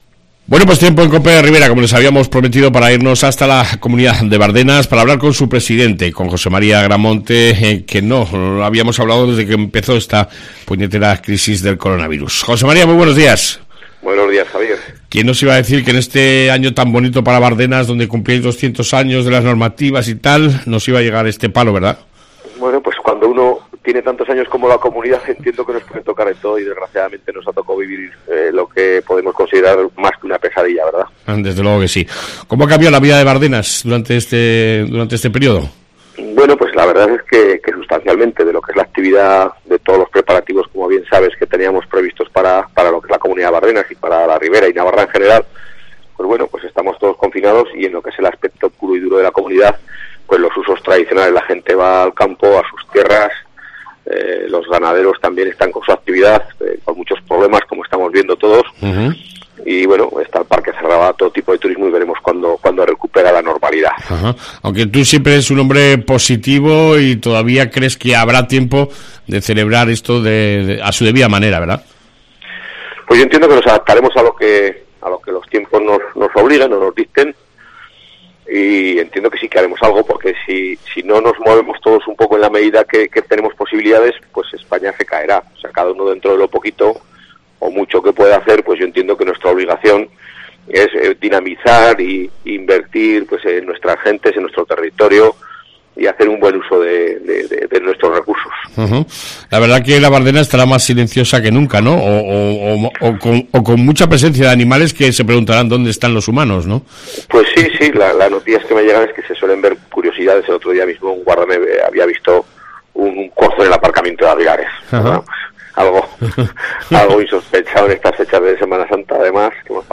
AUDIO: El presidente de Bardenas , José Mª Agreamonte , nos explica como están viviendo esta Crisis del Coronavirus desde la Comunidad